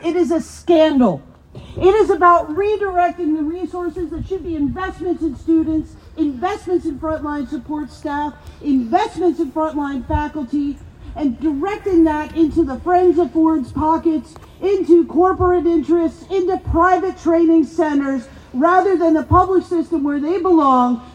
With chants of ‘our college, our future,’ a large number of faculty, support staff, students and union supporters rallied at Loyalist College Wednesday afternoon.